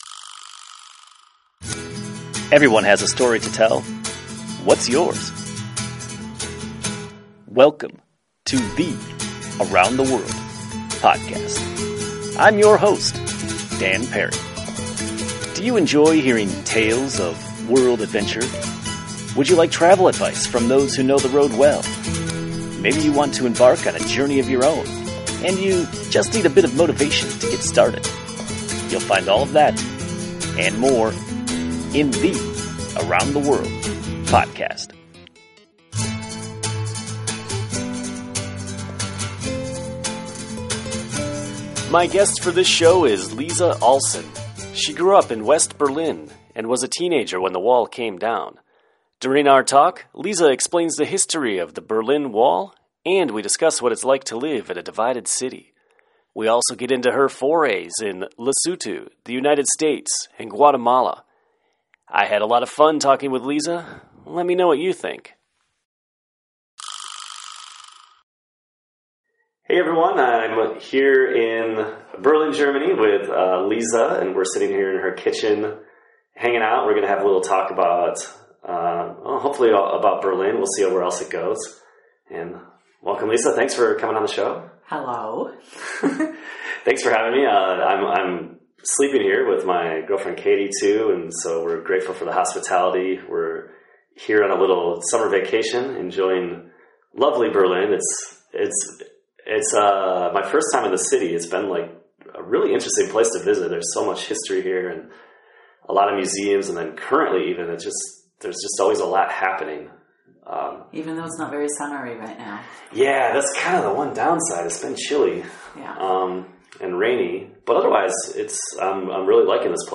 A podcast with a native Berliner.
We sat down in her kitchen for a long chat. She starts with a great summary of Germany's separation after World War II and the Berlin Wall's construction 1961. Then she tells the story of where she was when the wall fell.